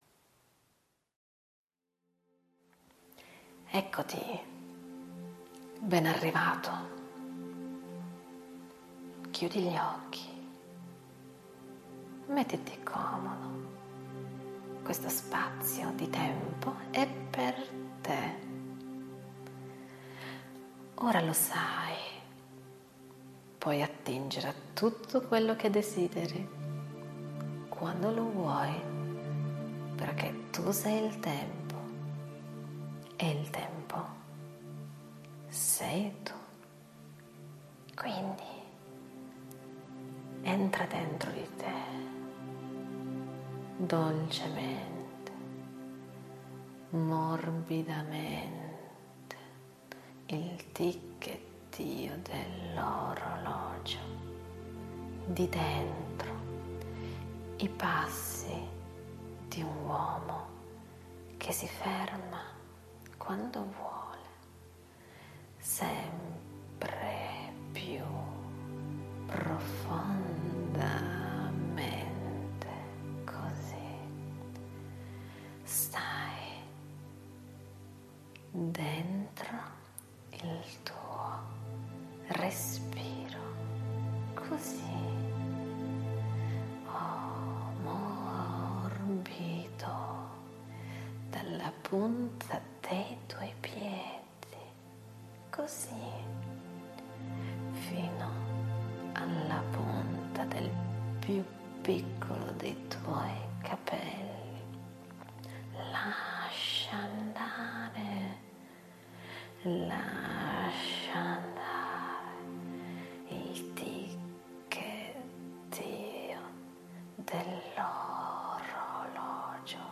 Goditi la mia voce e i benefici che porterà.